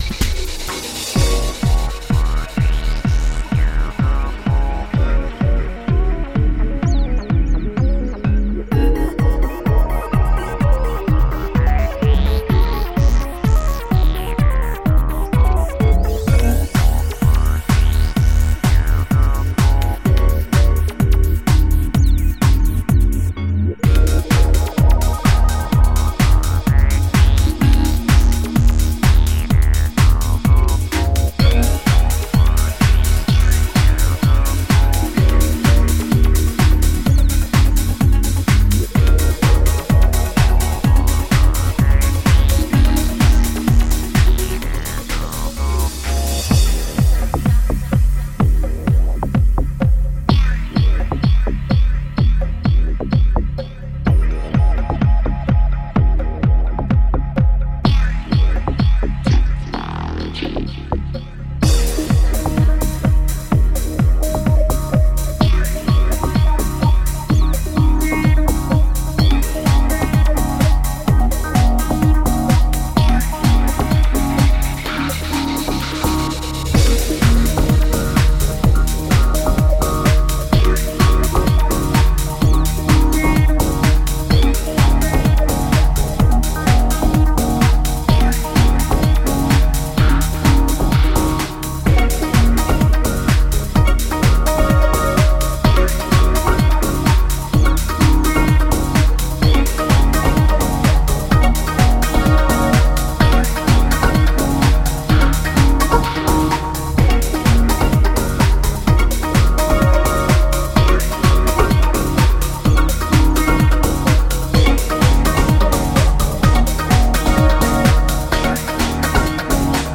spaced-out synths and electronic house sounds